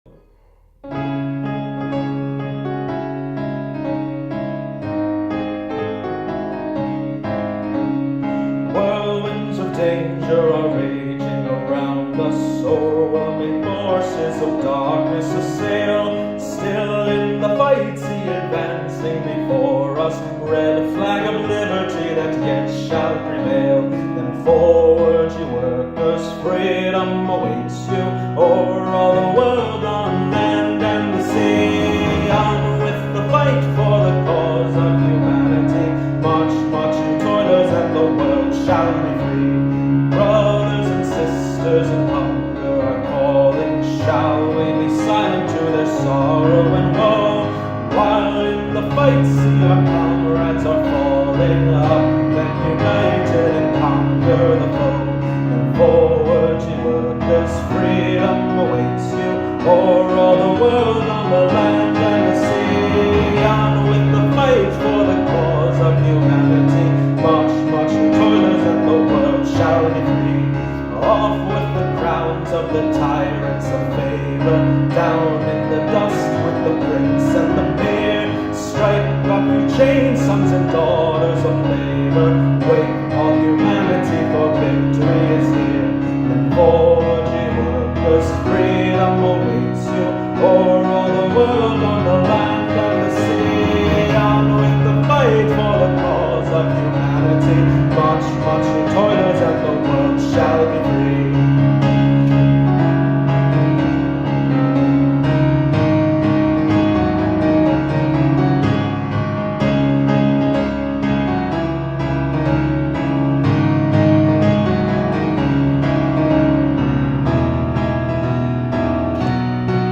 Audio with English lyrics and piano accompaniment